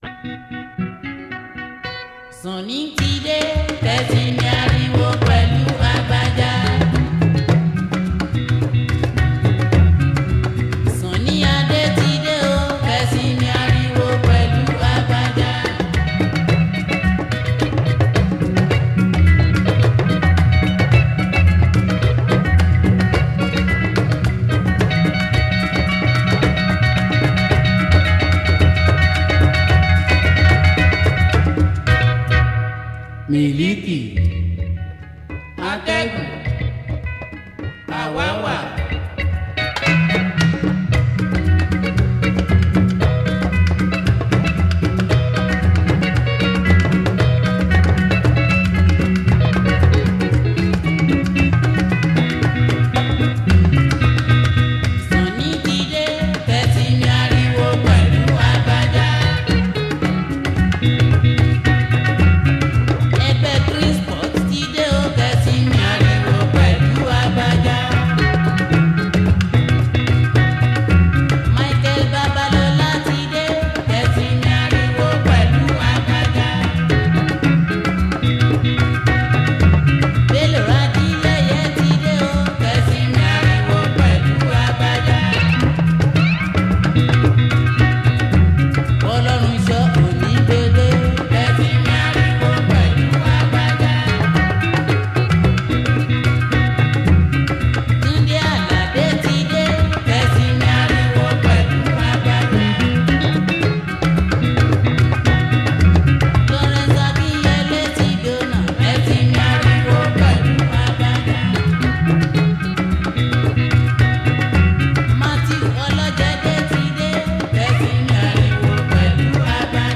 is a Nigerian jùjú singer